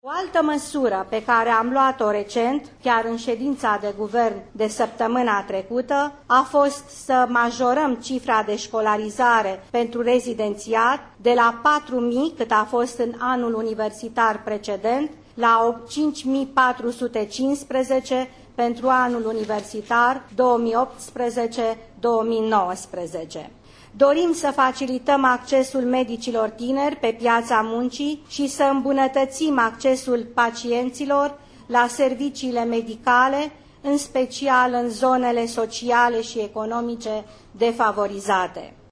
În mesajul adresat studenților Universității de Medicină și Farmacie Craiova, cu ocazia participării la deschiderea noului an universitar, premierul Viorica Dăncilă a reiterat dorința guvernului de a facilita accesul medicilor tineri pe piața muncii și a anunțat că a fost majorat numărul locurilor la rezidențiat, pentru acest an universitar.